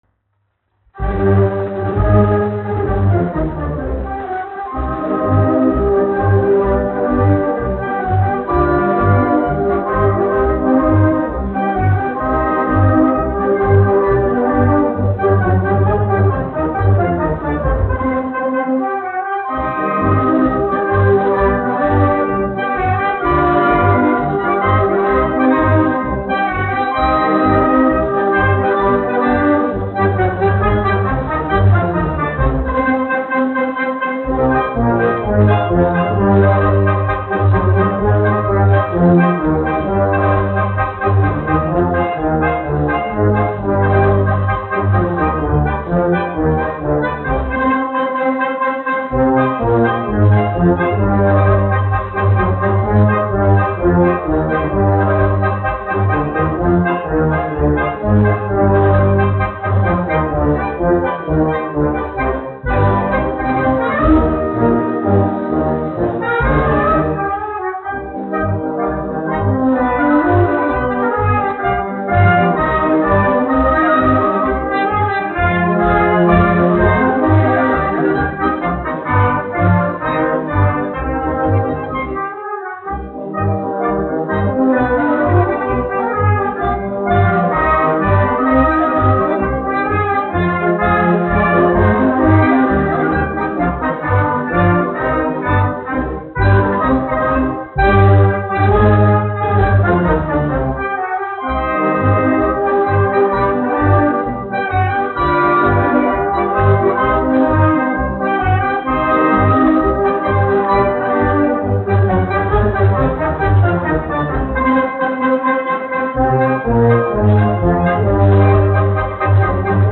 1 skpl. : analogs, 78 apgr/min, mono ; 25 cm
Marši
Pūtēju orķestra mūzika
Skaņuplate